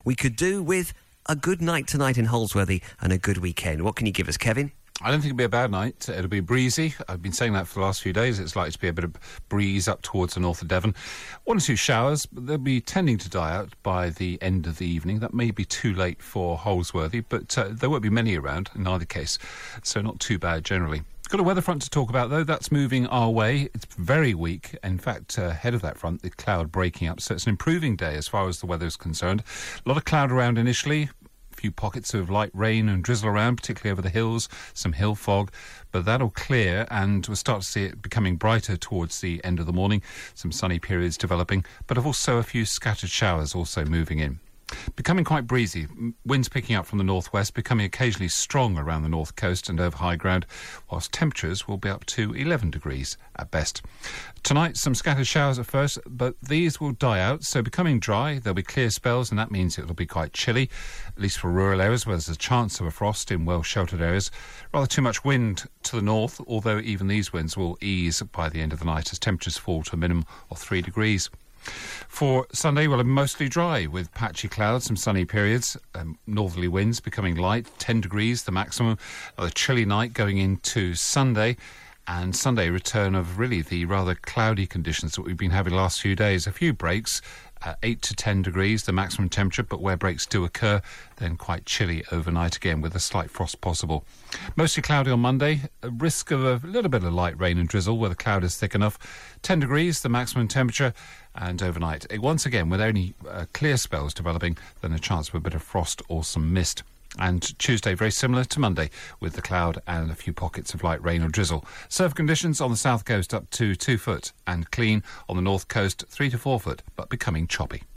5 day forecast for Devon from 8.35AM on 29 November